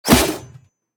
metal1.ogg